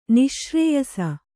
♪ niśrēyasa